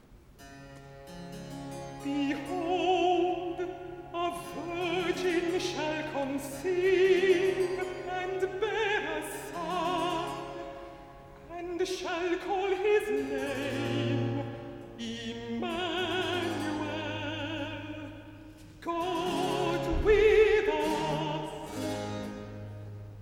Recitative-alto